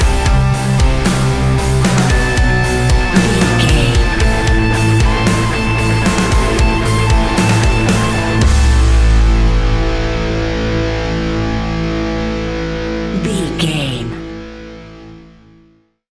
Ionian/Major
A♭
hard rock
guitars
instrumentals